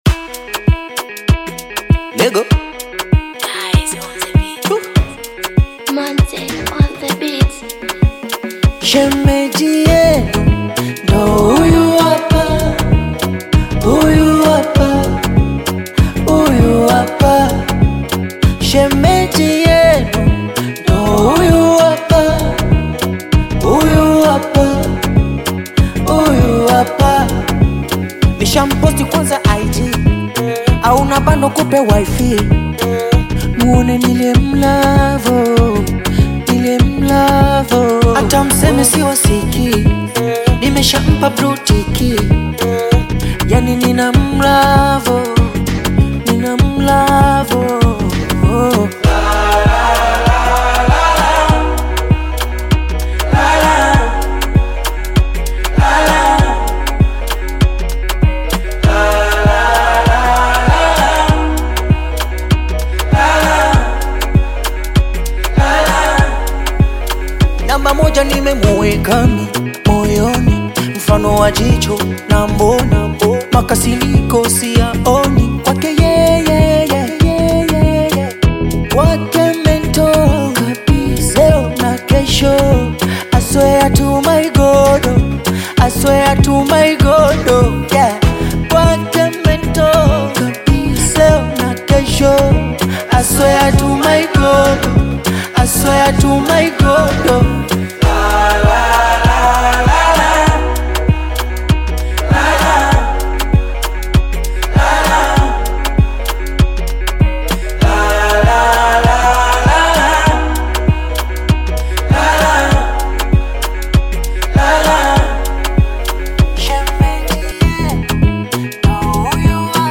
Bongo Flava music track
Tanzanian Bongo Flava artist, singer and songwriter